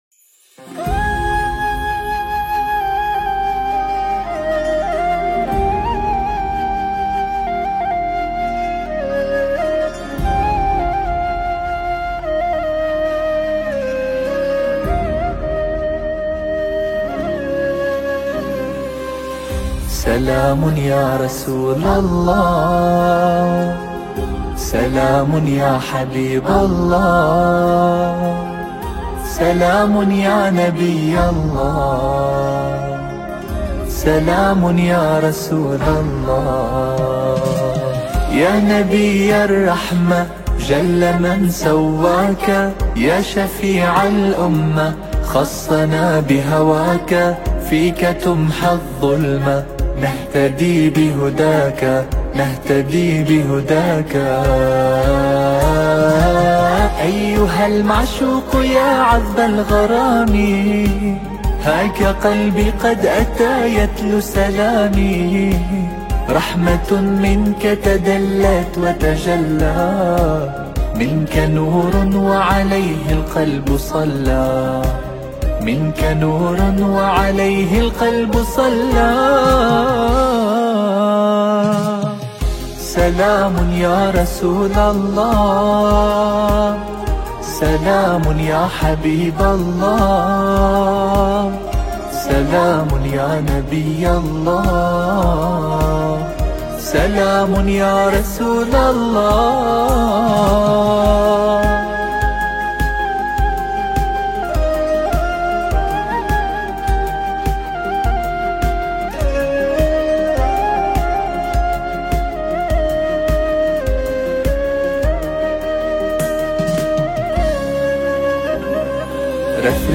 دانلود نماهنگ زیبای عربی